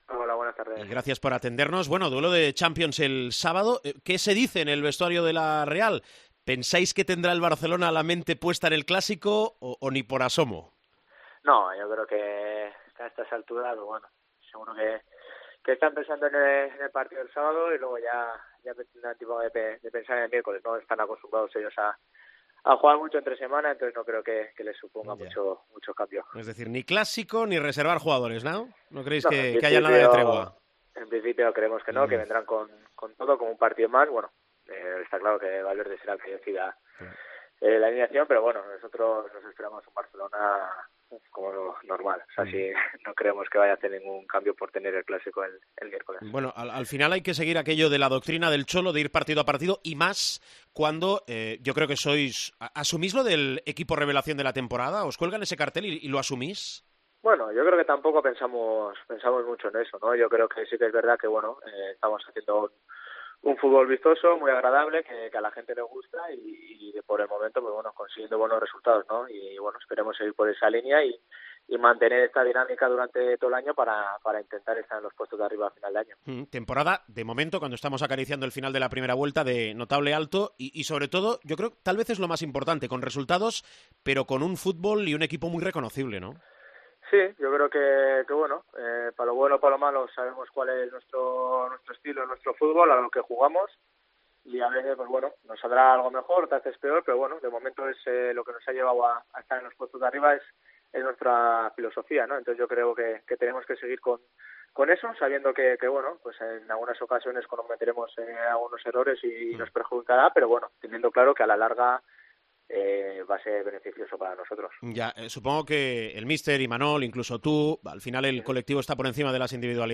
El jugador de la Real Sociedad Joseba Zaldúa ha dicho en Esports Cope que esperan el mejor Barça en el Reale Arena “seguro que están pensando en el partido del sábado y luego ya pensarán en el miércoles. Ellos están acostumbrados a jugar entre semana y no creo que les suponga mucho cambio”.